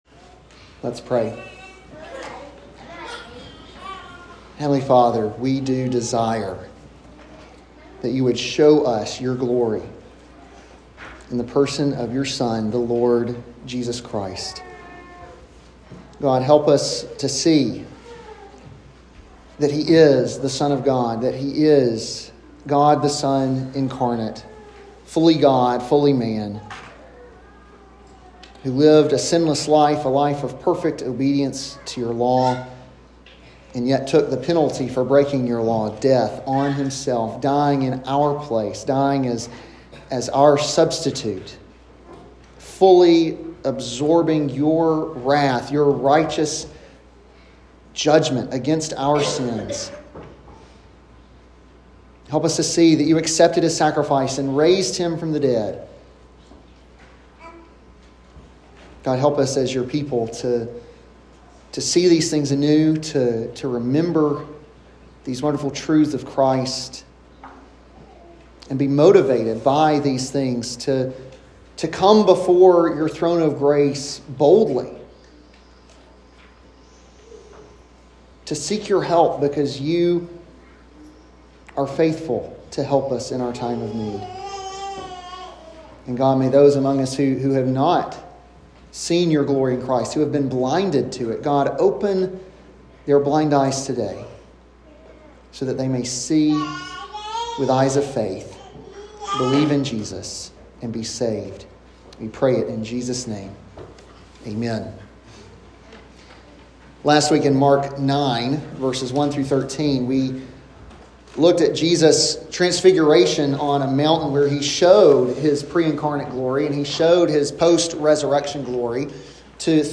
an expository sermon on Mark 9:14-29